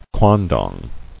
[quan·dong]